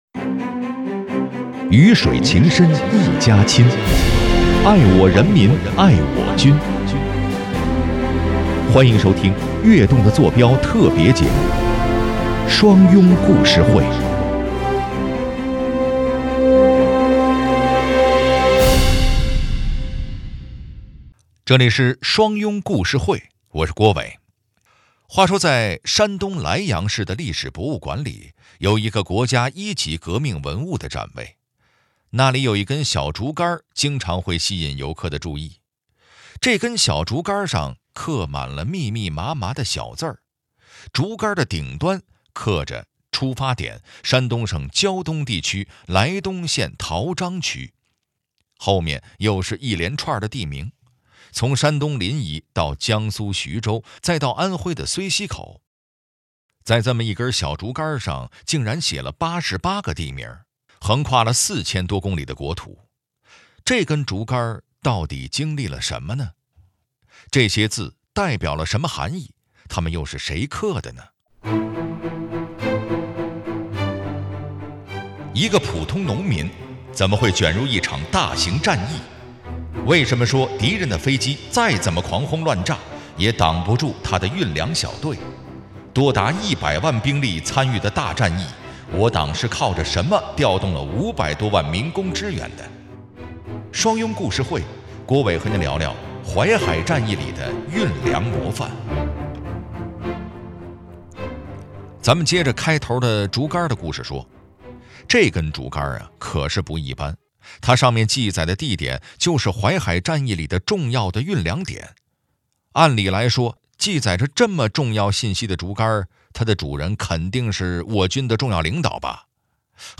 为更好地打造新时代双拥创建首善之区，北京市退役军人事务局在交通广播（FM103.9）推出双拥宣传栏目-双拥故事会，由交通广播资深主持人为大家讲述双拥人物事迹、红色家书、军旅风采、双拥史话等。